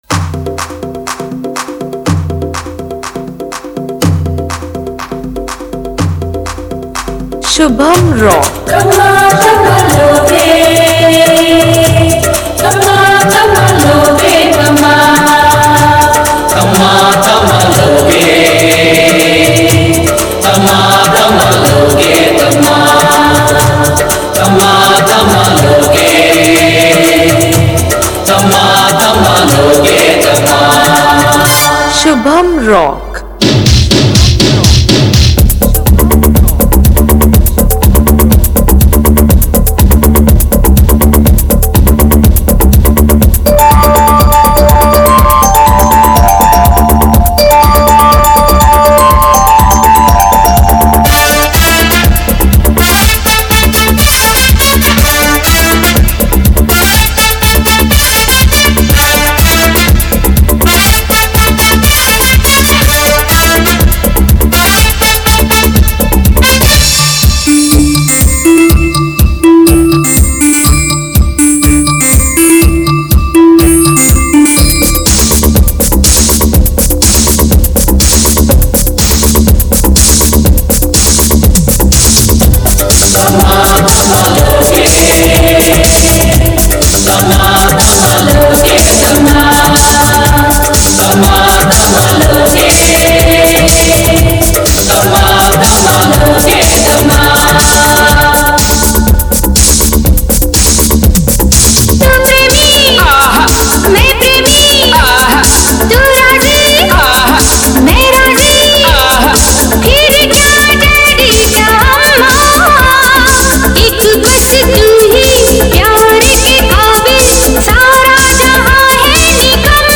Mela Competition Filters Song